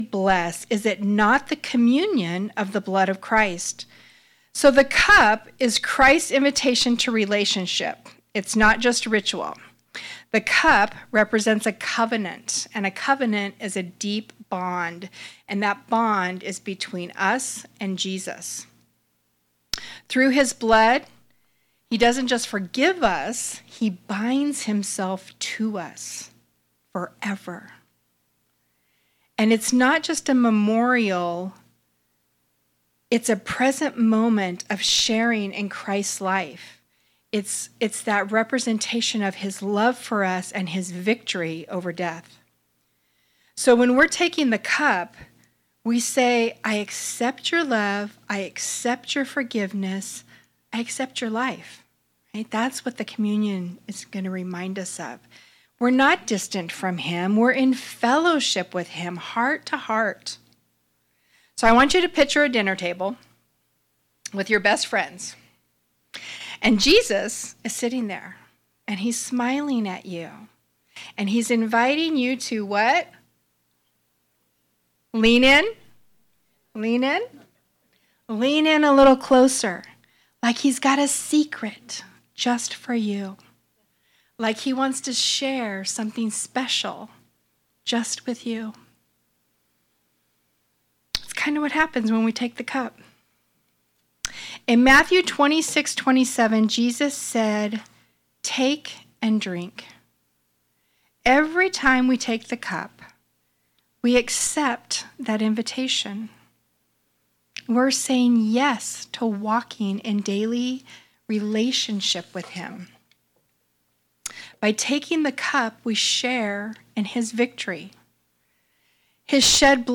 Sermons | Calvary Chapel Pahrump Valley
Guest Speaker